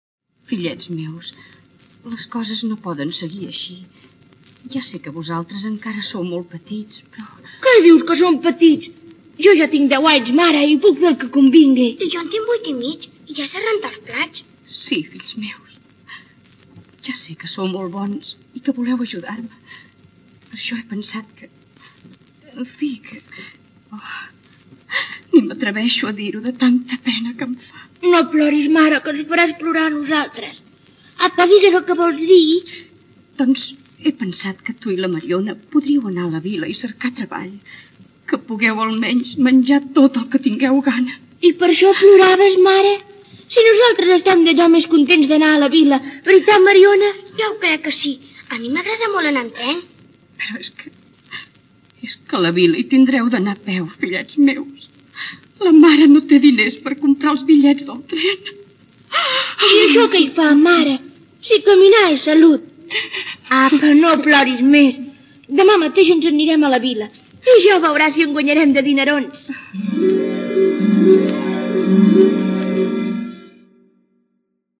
Comença el 1949 com actriu del quadre escènic de Radio Nacional, al Teatro Invisible, on va interpretar diverses obres dramàtiques i també contes infantils.